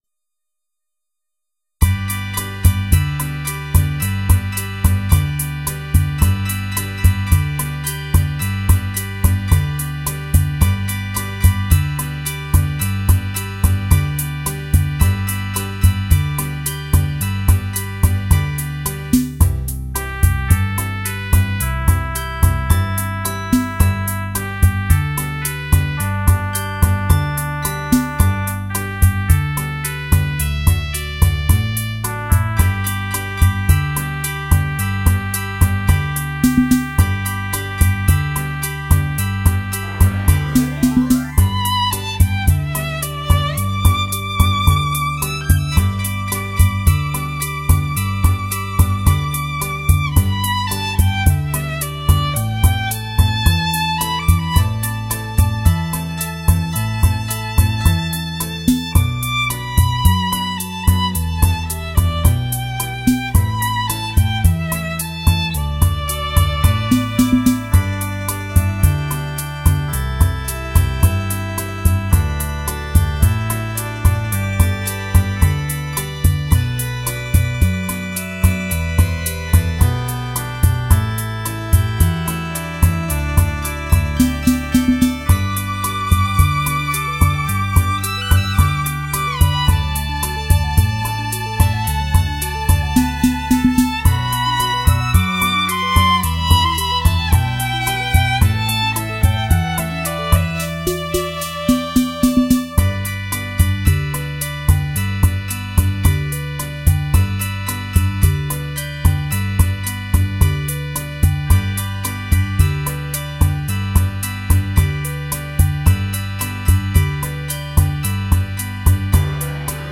小提琴与电子琴的新颖搭配来演奏人们熟悉和美妙动听的民乐
小提琴的优雅与电子琴的纯朴相辉相映，别具一格，雅俗共赏
有意想不到的艺术效果：它，使紧张工作的人们感受到恬静与轻松